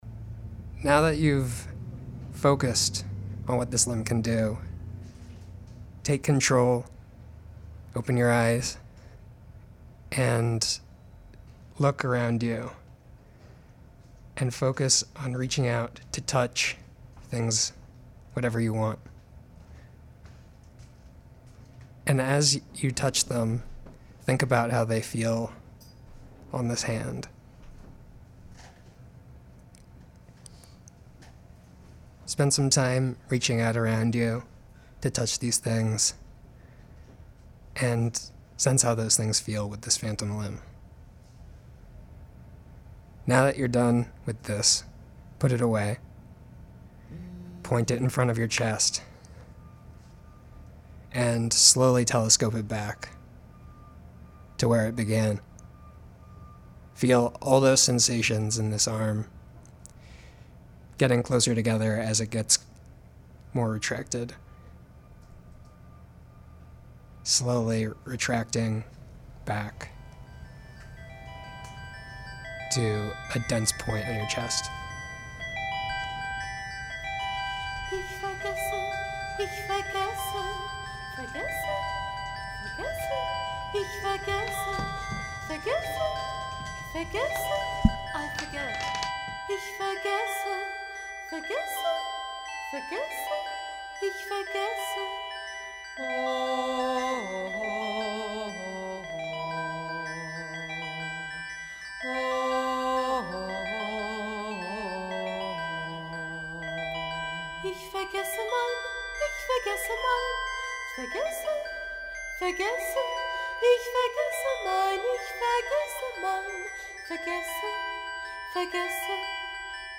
ASMR, haptics, triggers, trust